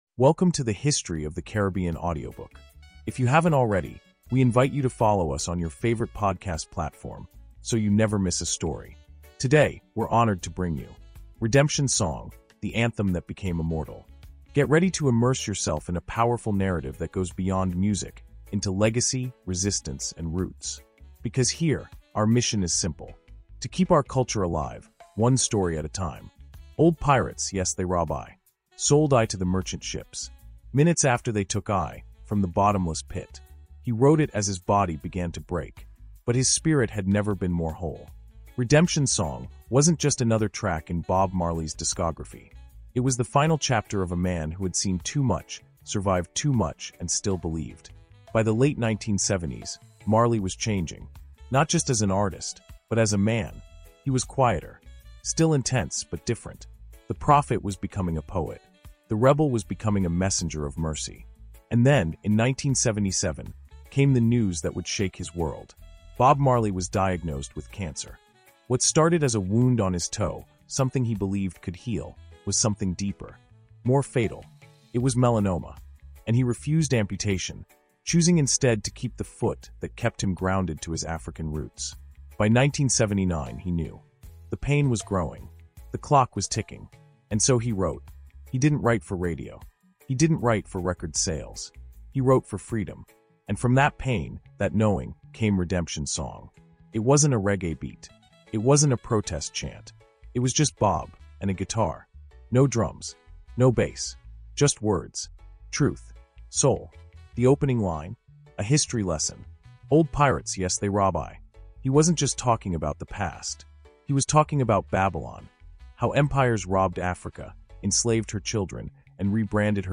Redemption Song – The Anthem That Became Immortal” is a soul-stirring audiobook journey into the heart of Bob Marley’s final masterpiece.